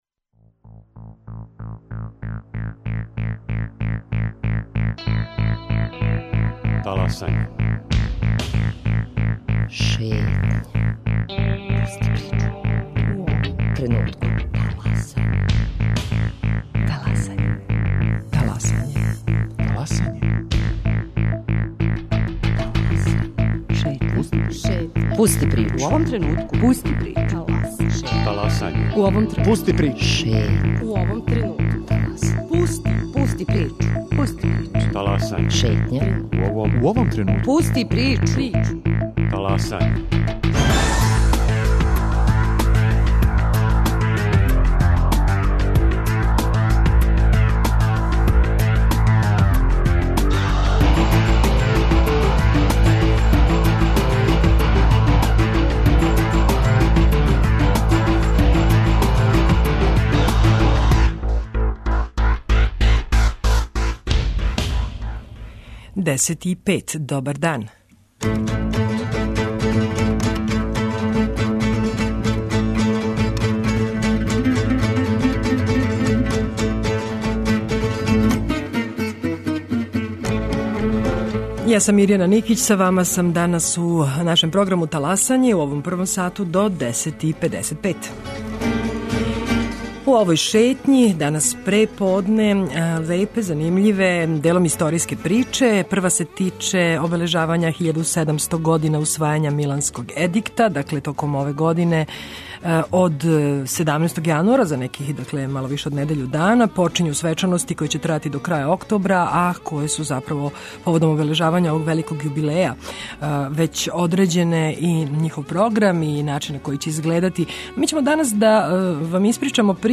Репортер Радио Београда 1 вратиће нас у детињство причом о једној од последњих бонбонџијских радњи у Београду.